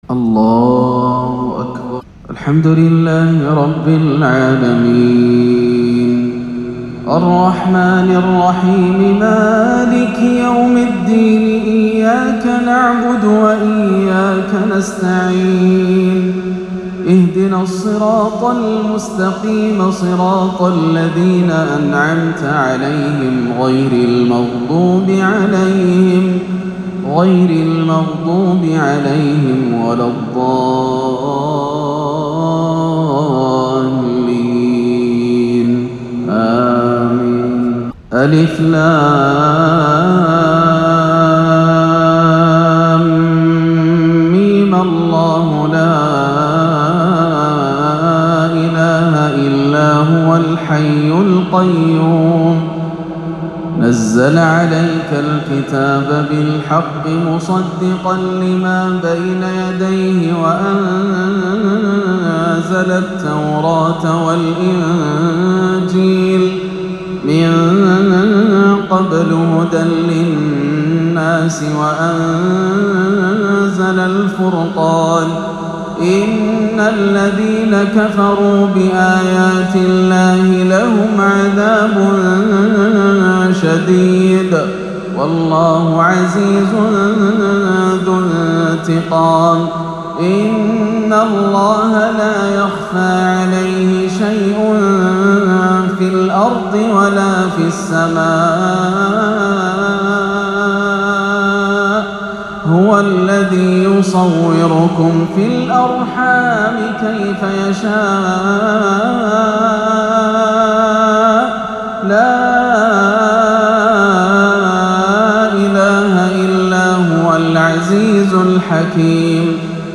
(للذين اتقوا عند ربهم جنات) تلاوة بديعة لفواتح سورة آل عمران - عشاء السبت 28-7 > عام 1439 > الفروض - تلاوات ياسر الدوسري